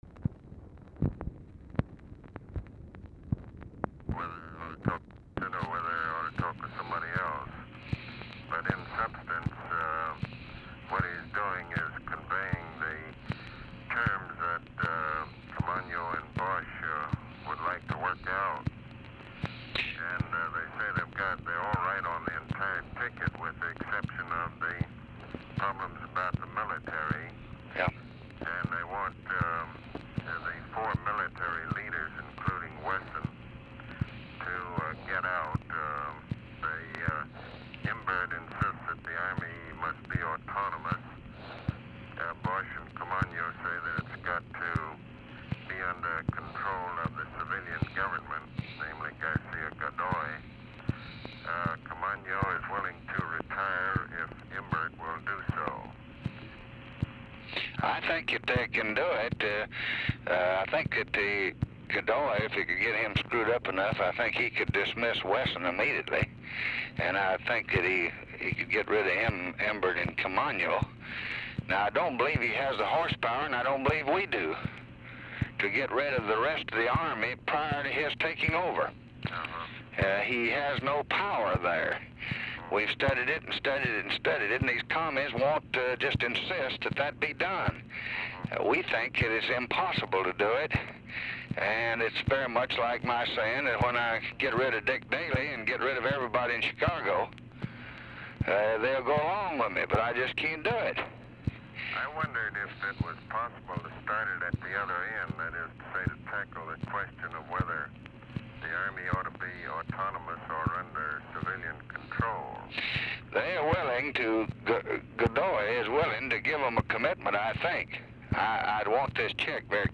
Telephone conversation
RECORDING STARTS AFTER CONVERSATION HAS BEGUN
Format Dictation belt
LBJ Ranch, near Stonewall, Texas